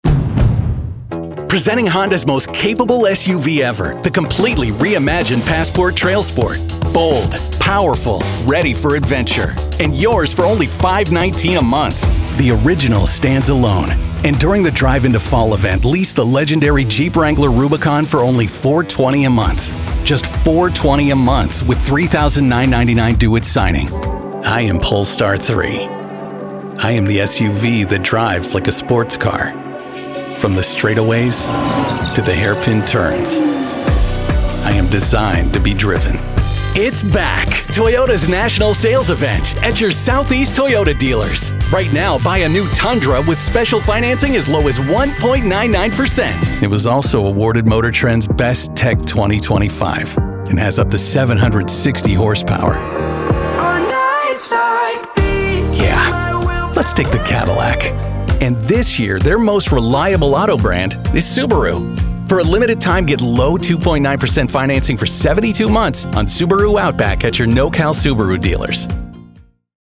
Automotive